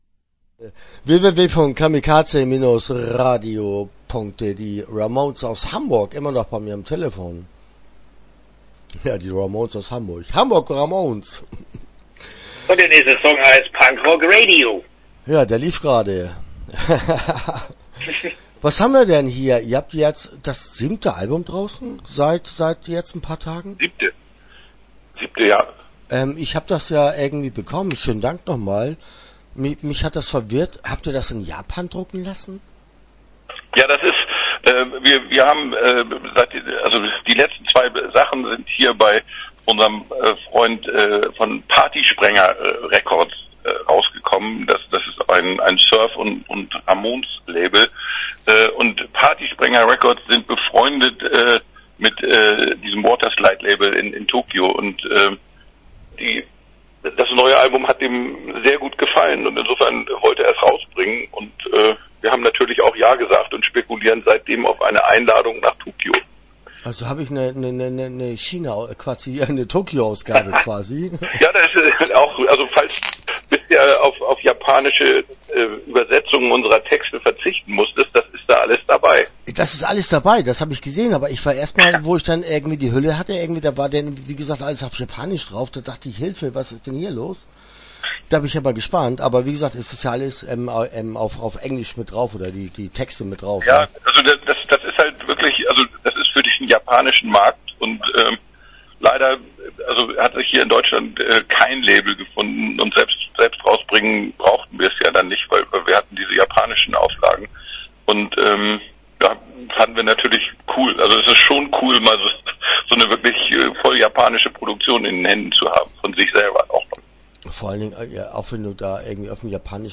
Hamburg Ramönes - Interview Teil 1 (13:26)